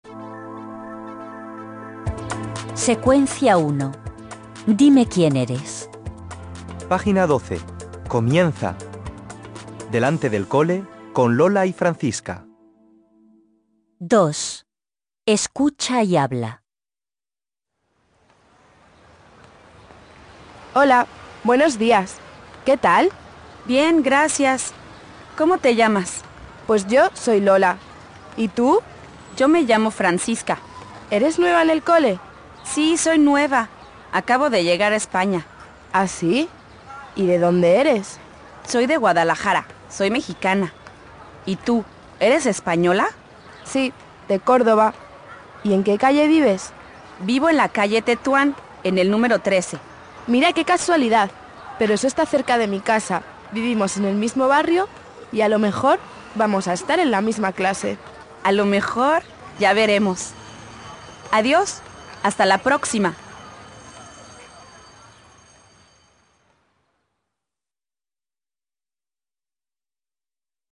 Comprensión oral
lola-y-francisca-dialogo.mp3